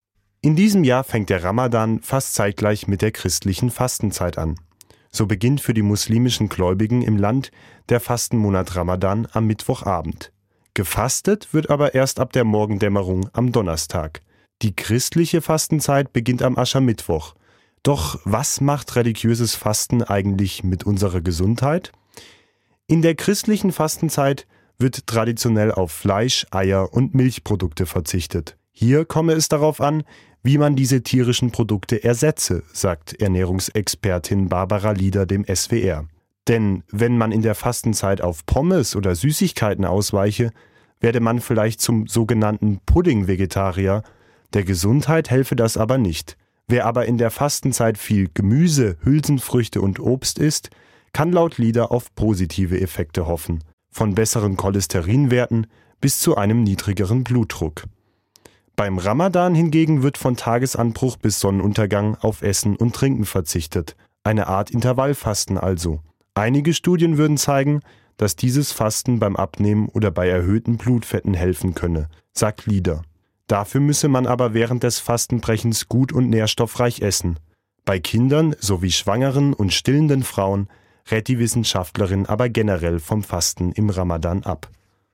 Interview zum Beginn von Fastenzeit und Ramadan: Wie gesund ist religiöses Fasten?